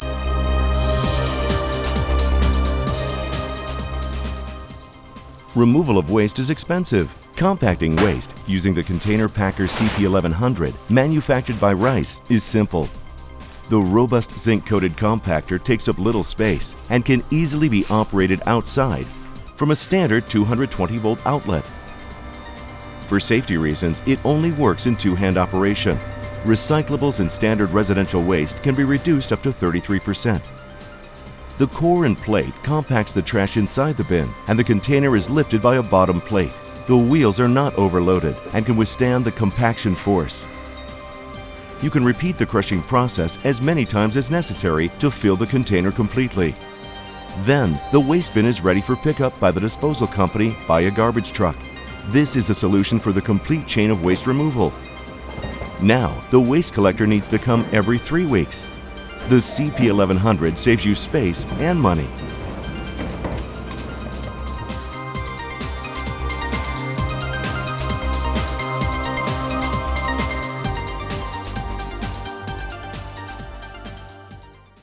Packer unit Typ CP 1100 for 1,1-m³-Container to EN 840
In this filmlet (1:15 Min) you will see the packer unit CP1100 in use:
Muellverdichter-container-packer-CP1100_en.ogg